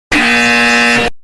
Song: Extremely Loud Incorrect Buzzer.mp3